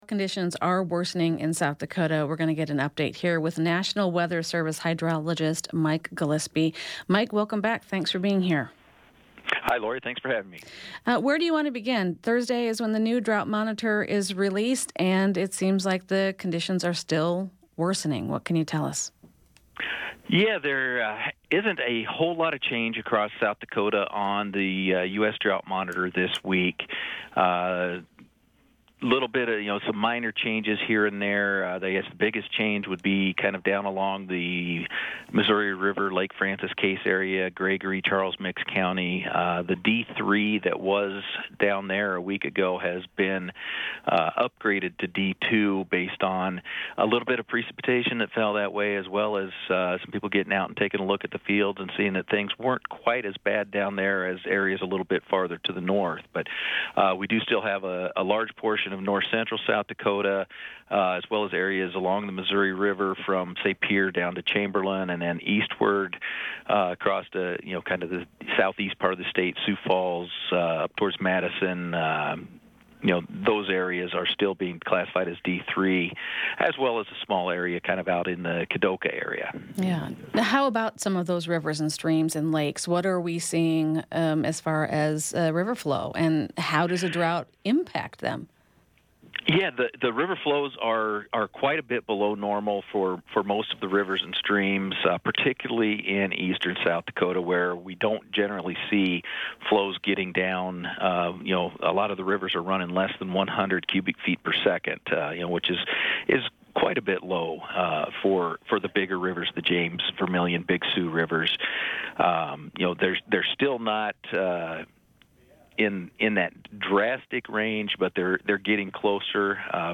This story comes from a recent interview on SDPB's weekday radio program, "In the Moment."